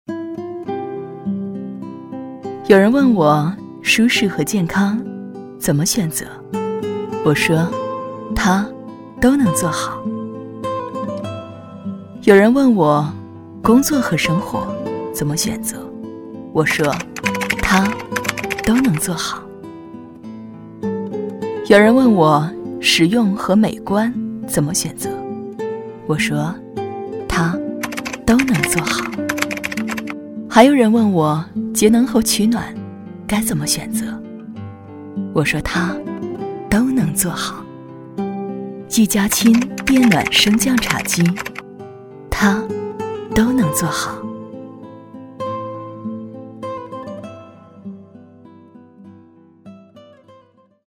女267-广告— 一家亲茶几
女267专题广告解说彩铃 v267
女267-广告--一家亲茶几.mp3